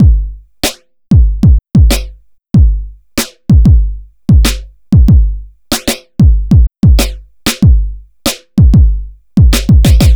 Track 13 - Drum Break 03.wav